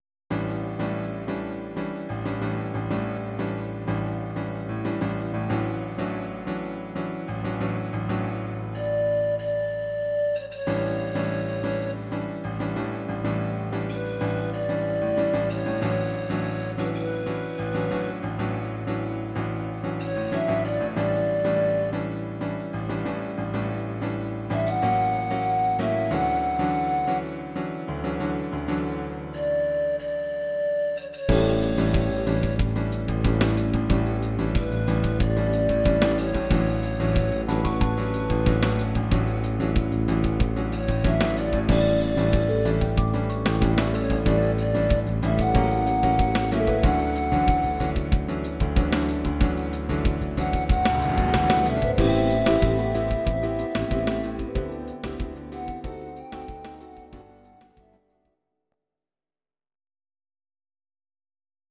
Audio Recordings based on Midi-files
Ital/French/Span, 2000s